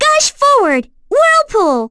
Lilia-Vox_Skill2.wav